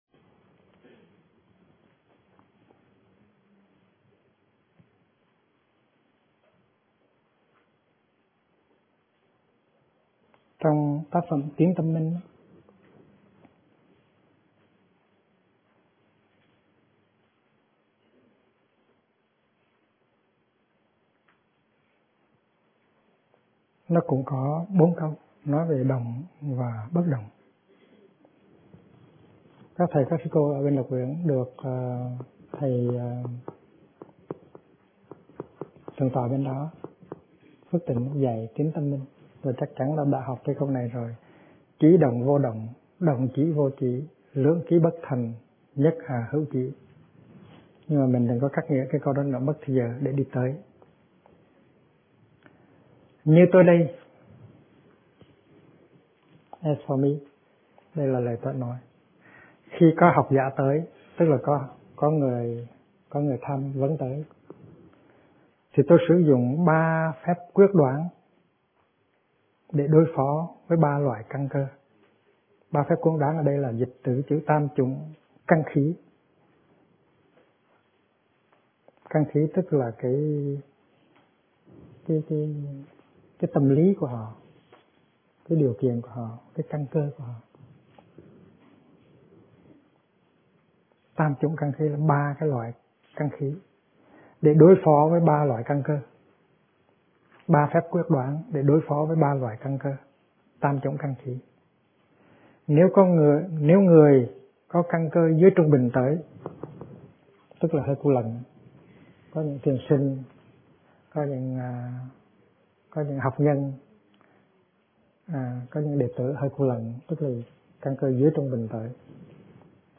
Kinh Giảng Ai Đang Đi Tìm Phật Pháp - Thích Nhất Hạnh